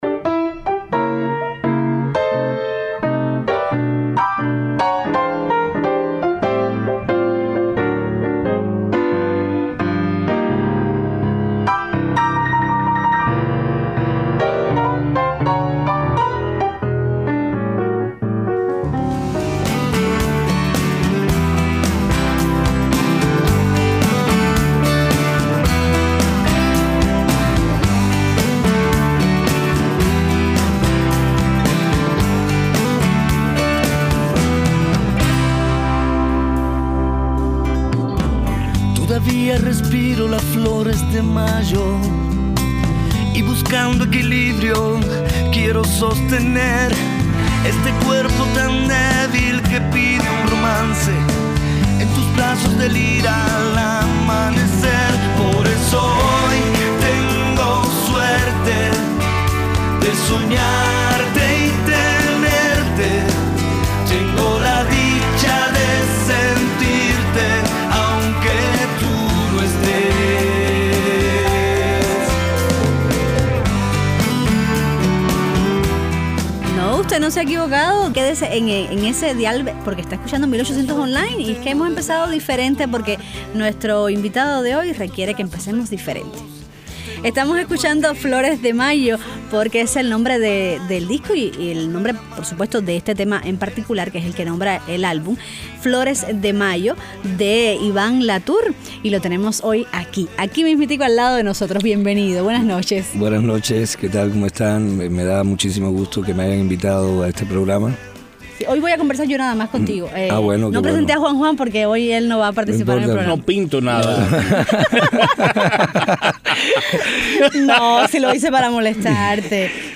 Entrevista a la cantante cubana Daymé Arocena sobre su nuevo álbum Al-Kemi